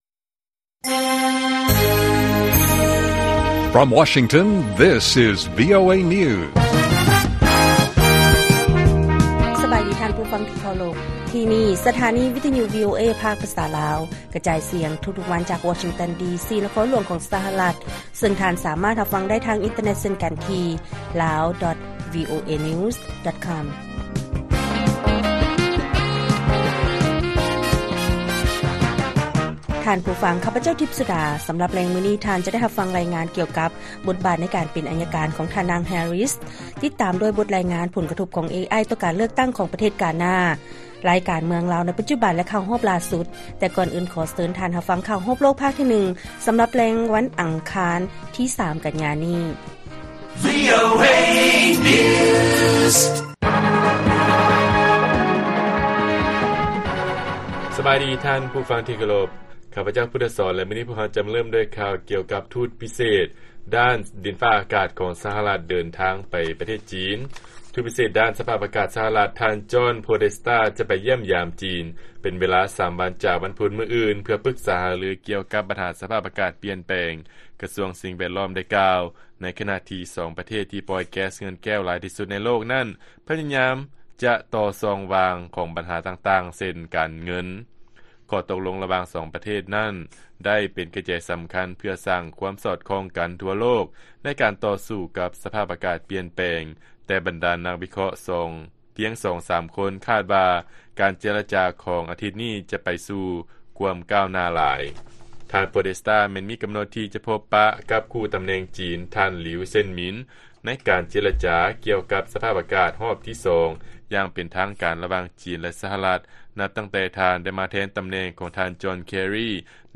ລາຍການກະຈາຍສຽງຂອງວີໂອເອລາວ: ທູດລະດັບສູງດ້ານດິນຟ້າອາກາດ ສະຫະລັດ ຈະໄປຢ້ຽມຢາມ ຈີນ ສຳລັບການເຈລະຈາ